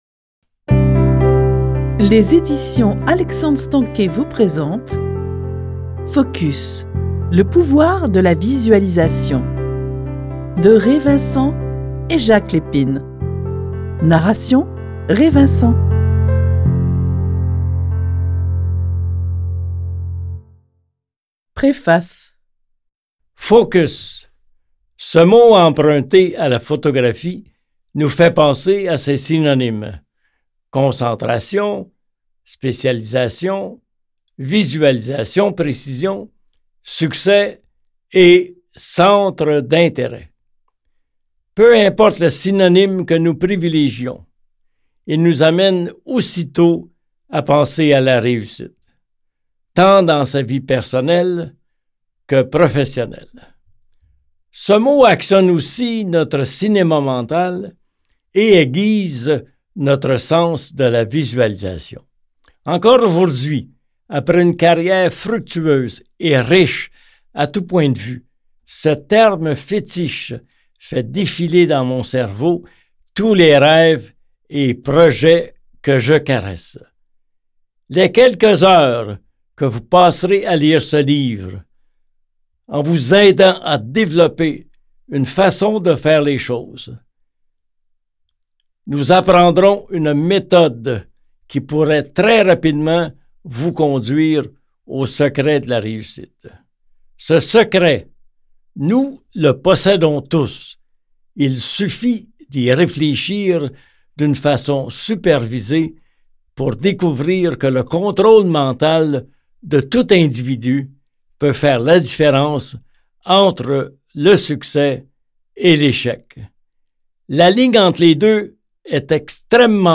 Les auteurs, grâce à leurs observations des gens qui ont atteint le succès, ont réuni les méthodes utilisées par ces personnes à succès et décrites dans ce livre audio pour vous aider à obtenir tout ce que vous rêvez et désirez obtenir dans la vie.